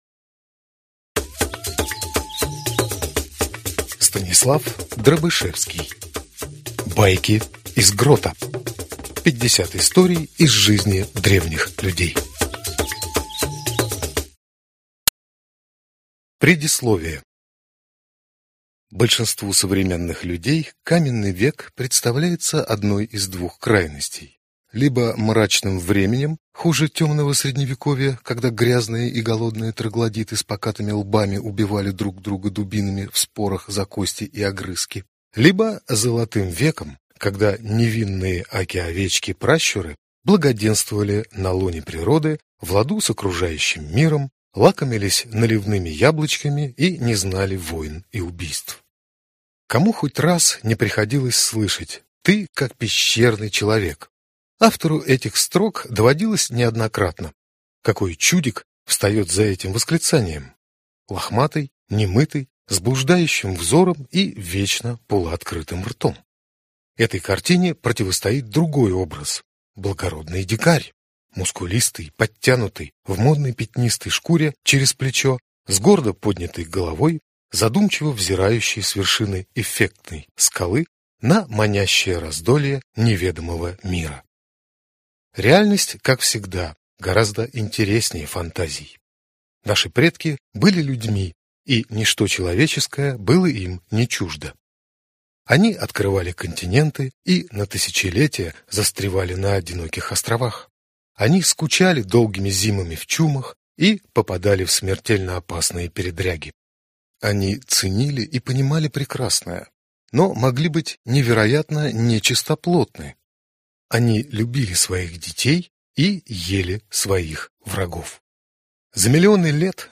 Аудиокнига Байки из грота. 50 историй из жизни древних людей | Библиотека аудиокниг